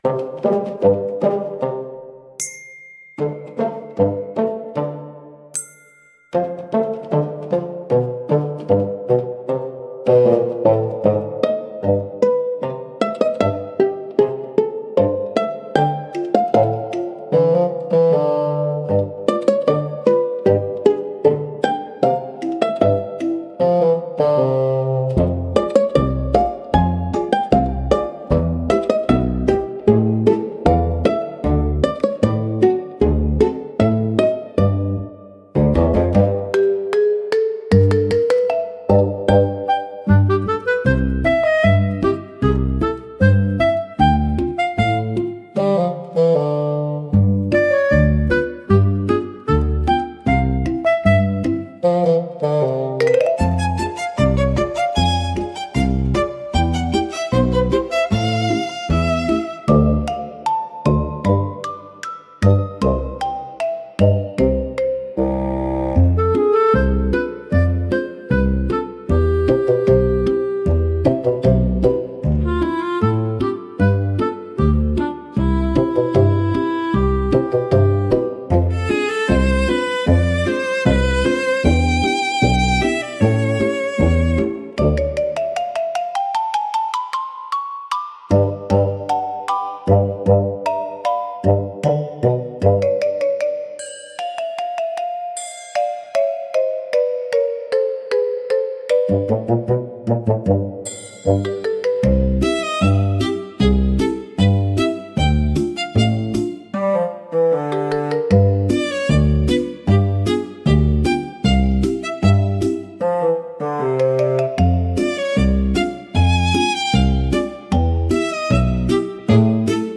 おとぼけは、バスーンとシロフォンを主体としたコミカルでドタバタした音楽ジャンルです。